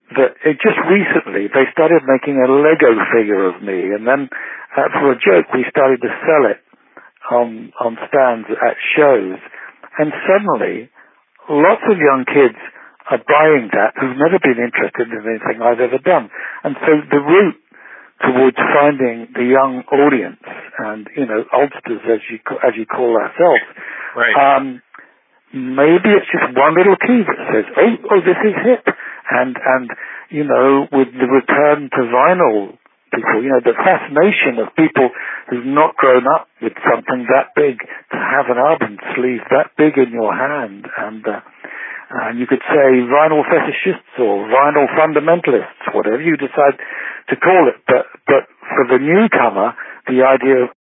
Fixing muffled sound
Dial-in plenty (30dB) of treble boost.
BTW the phone they’re using does not have anything above 4kHz,
so anything above 4kHz is artefacts which should be filtered out.
that definitely sounds better.
so, definitely making progress…now the question is, can I get rid of the high-pitch, kind of tinny echo in the background?